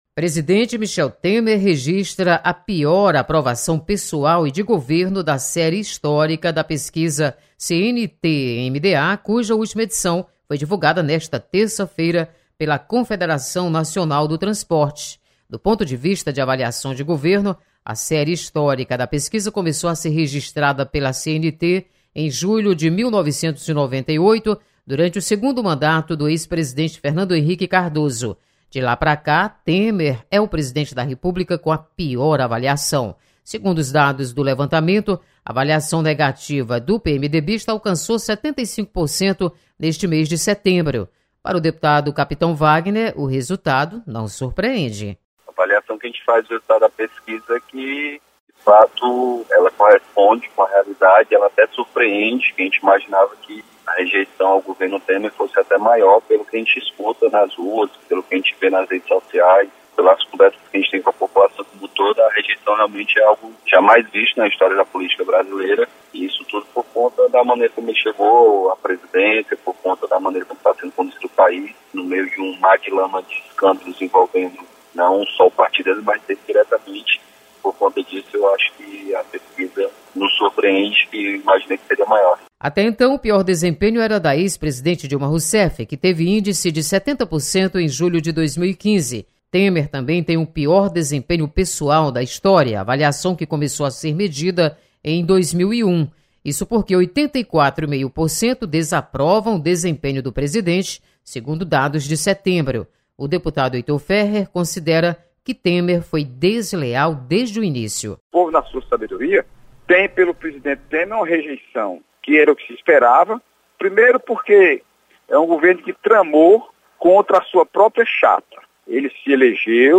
Deputados comentam sobre avaliação do governo Temer.